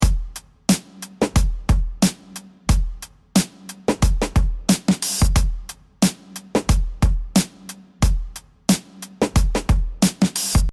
The next thing we needed is a looped beat. The hallmark of good hip hop is the single looped bassline and beat that repeats pretty much all through the song.
beat.mp3